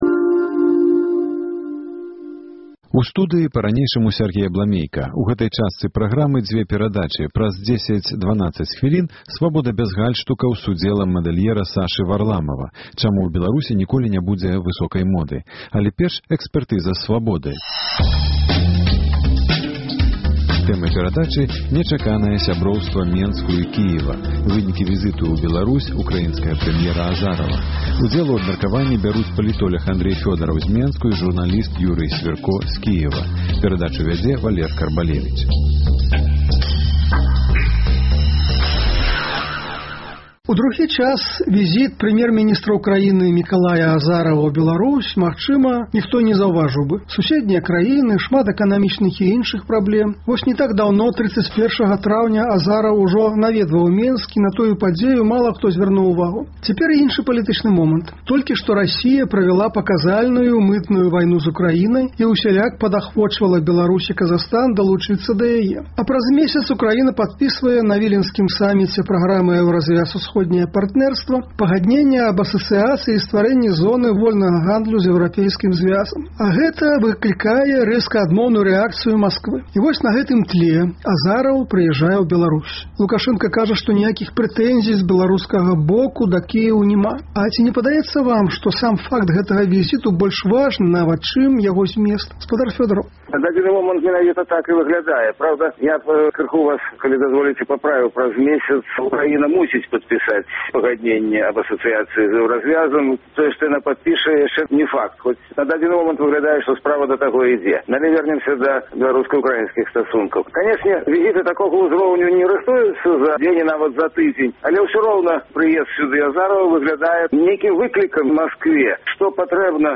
Чаму Менск дэманструе ляяльнасьць да палітыкі Украіны? Як такая пазыцыя Беларусі паўплывае на беларуска-расейскія адносіны? Гэтыя пытаньні абмяркоўваюць за круглым сталом